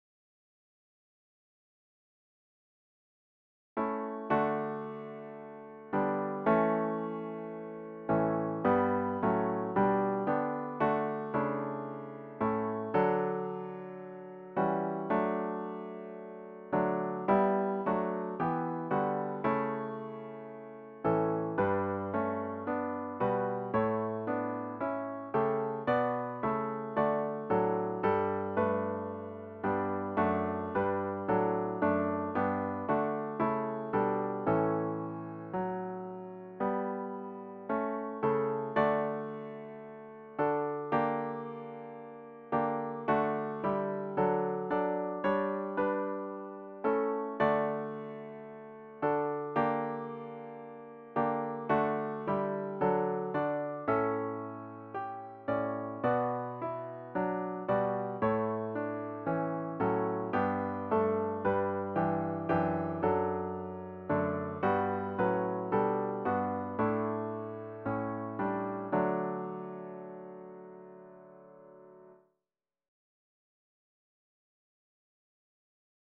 About the Hymn